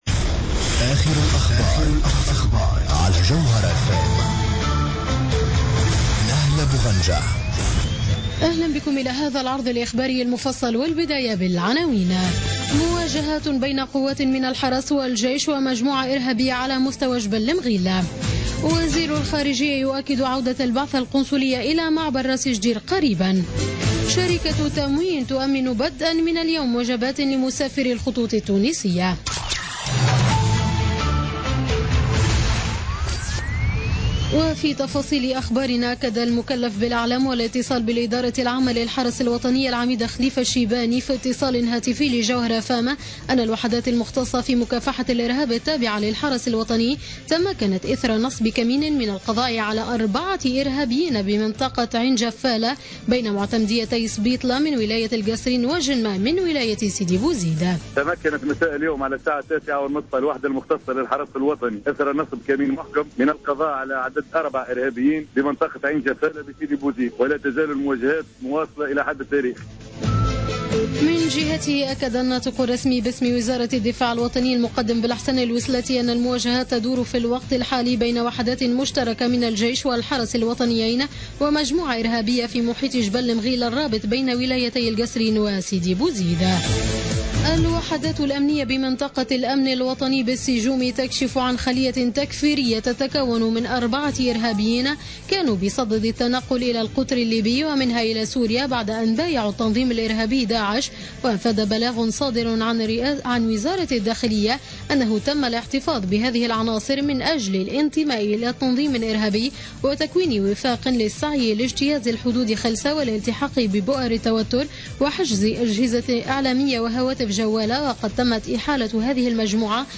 نشرة أخبار منتصف الليل ليوم الثلاثاء غرة مارس 2016